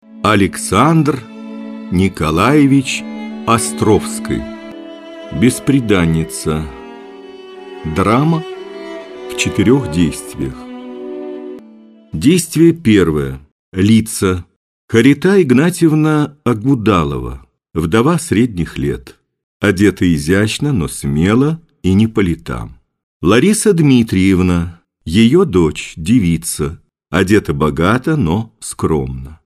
Аудиокнига Пьесы | Библиотека аудиокниг